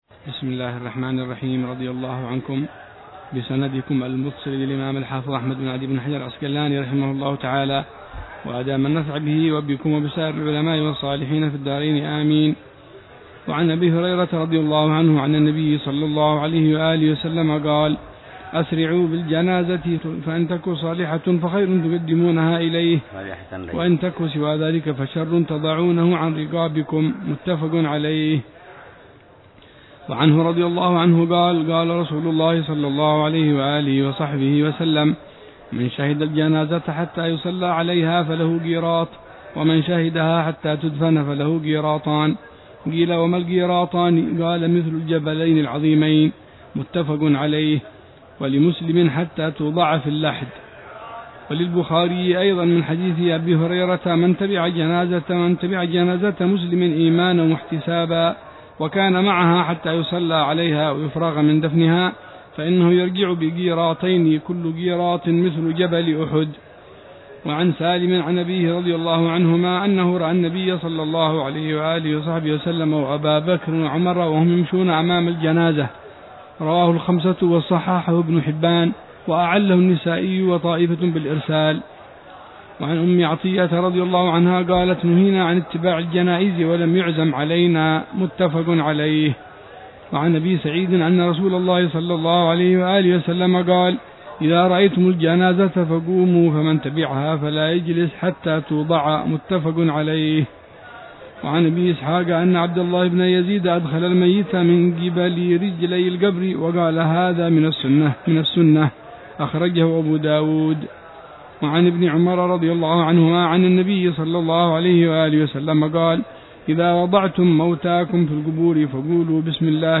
درس بلوغ المرام - 135- ، باب الجنائز: شرح الأحاديث المتعلقة باتباع الجنازة وحملها
شرح الحبيب عمر بن حفيظ على كتاب بلوغ المرام من أدلة الأحكام للإمام الحافظ أحمد بن علي بن حجر العسقلاني، مختصر يشمل